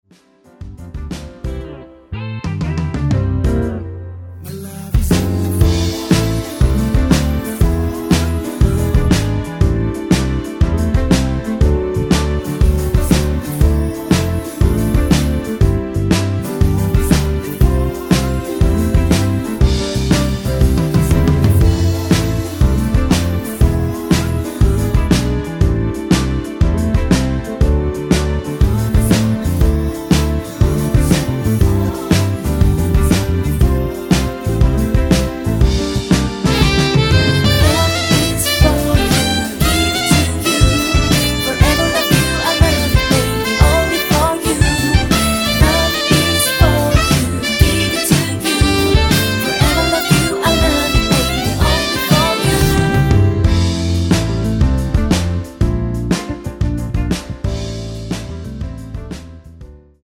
코러스 포함된 MR 입니다.(미리듣기 참조)
앞부분30초, 뒷부분30초씩 편집해서 올려 드리고 있습니다.